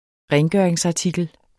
Udtale [ ˈʁεːnˌgɶˀɐ̯eŋsɑˌtigəl ]